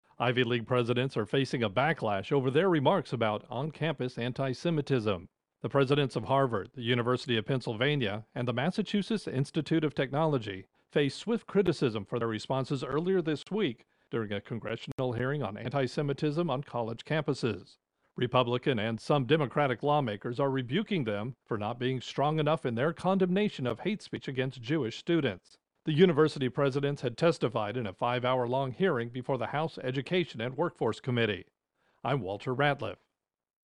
Intro and voicer for Education College Presidents Backlash